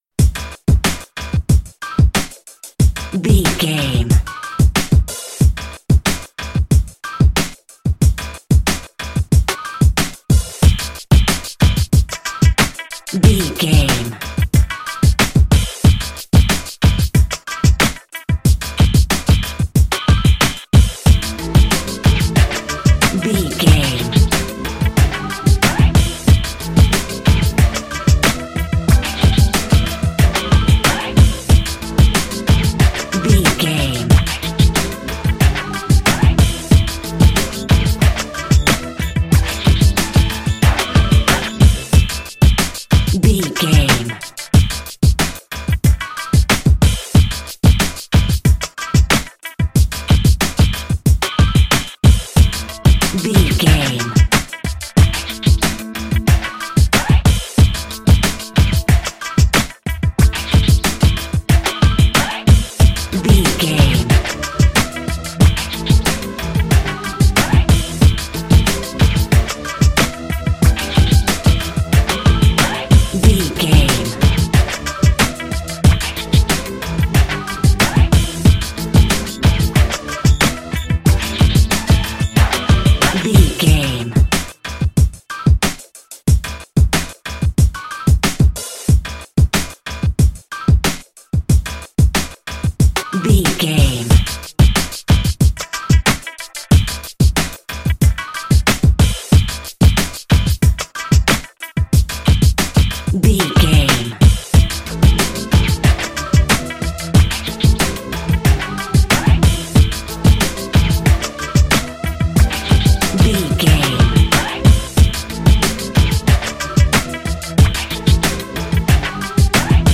Aeolian/Minor
DOES THIS CLIP CONTAINS LYRICS OR HUMAN VOICE?
drum machine
synthesiser
percussion